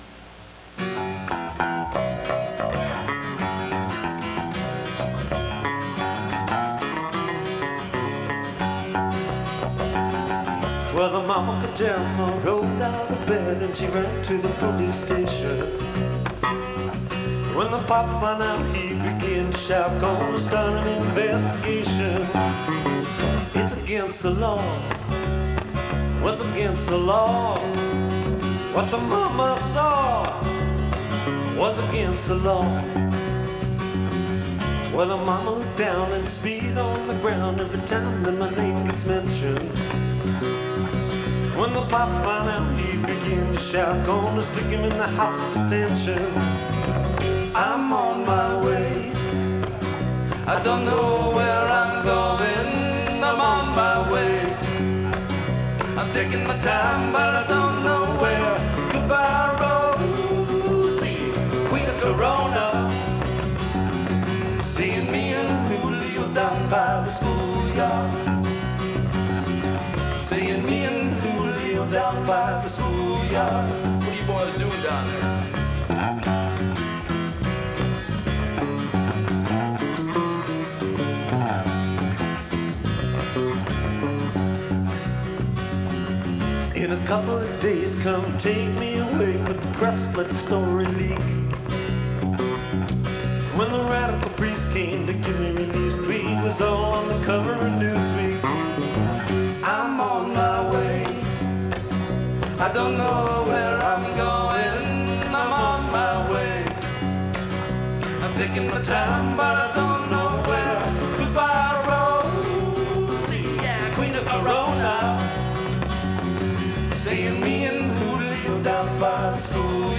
Category Rock/Pop
Studio/Live Live
guitar and vocals
bass, lead guitars and vocals
Recorded Live at the RED BARN, Peoria Il. Aug. 1989